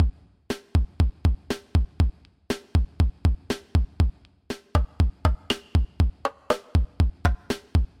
描述：康加
Tag: 120 bpm Disco Loops Drum Loops 1.35 MB wav Key : Unknown